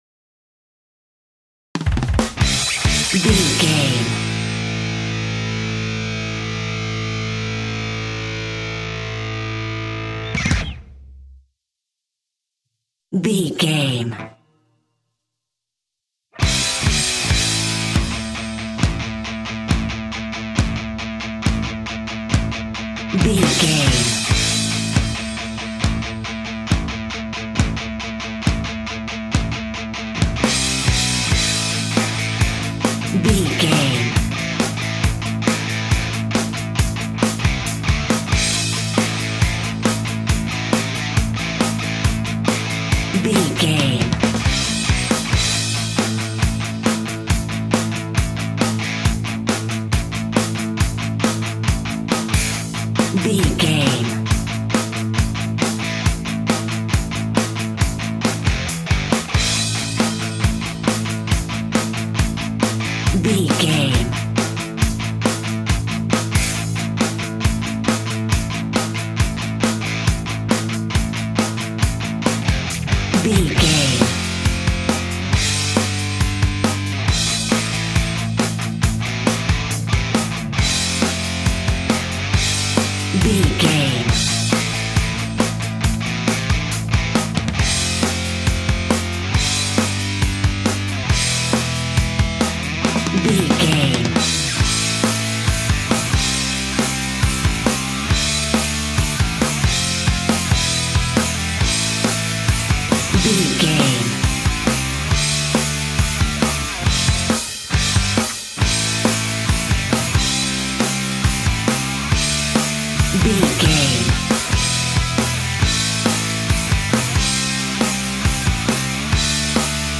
Epic / Action
Fast paced
Aeolian/Minor
hard rock
heavy metal
blues rock
instrumentals
Rock Bass
heavy drums
distorted guitars
hammond organ